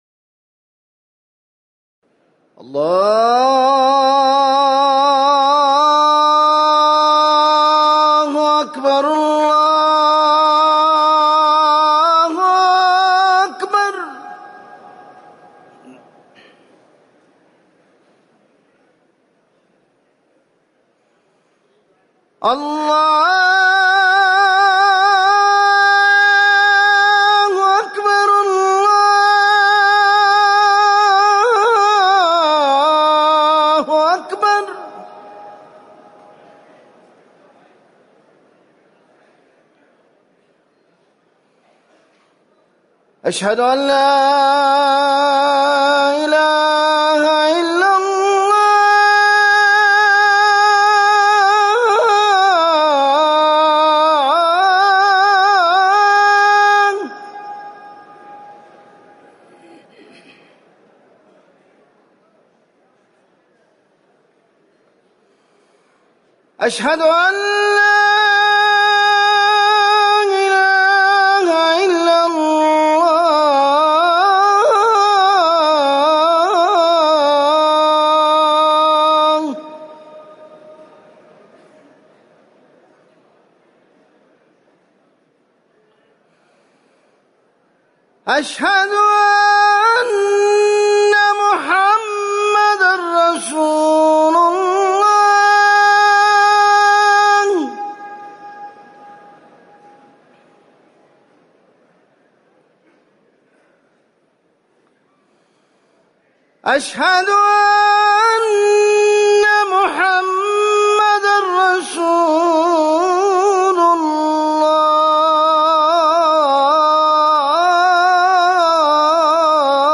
أذان المغرب - الموقع الرسمي لرئاسة الشؤون الدينية بالمسجد النبوي والمسجد الحرام
تاريخ النشر ٣ محرم ١٤٤١ هـ المكان: المسجد النبوي الشيخ